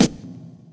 TREV_SNR.wav